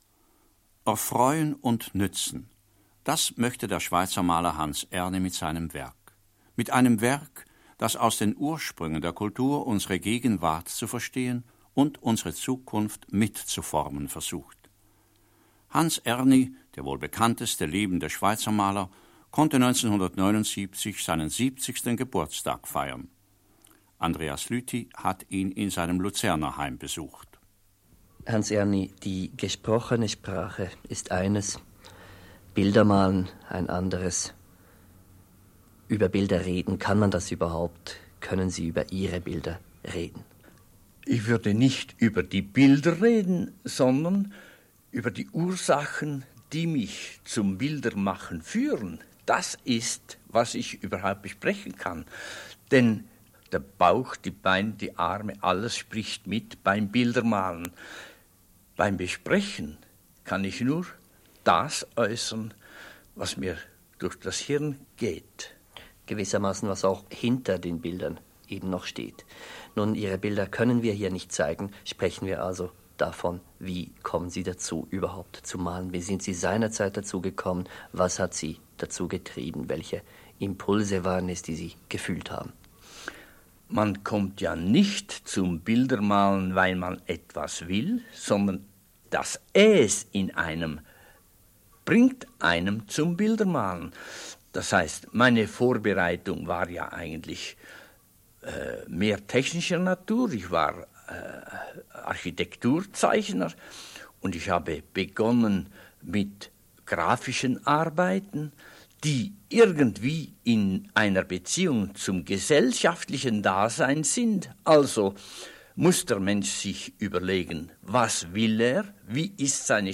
Gespräch mit Hans Erni anlässlich seines 70. Geburtstages